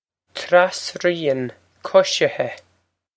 Pronunciation for how to say
Trass-ree-on Kush-ih-heh
This is an approximate phonetic pronunciation of the phrase.